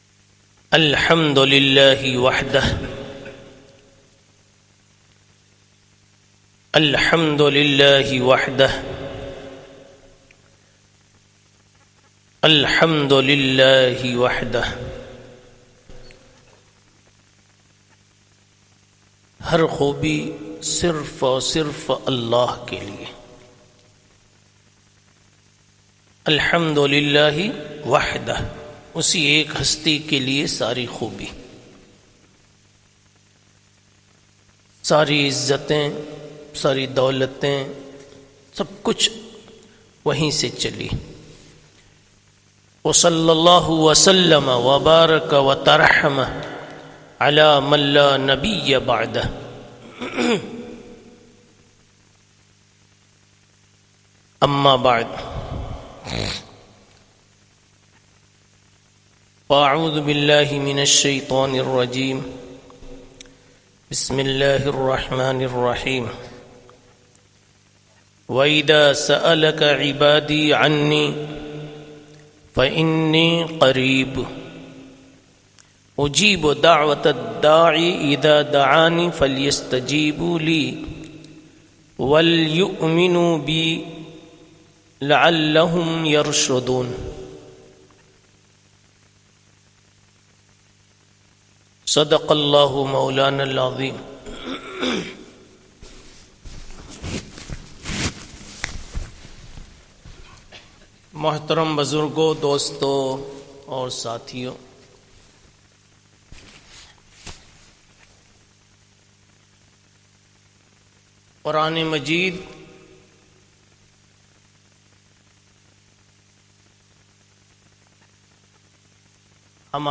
Masjid Adam, Ilford Recording Date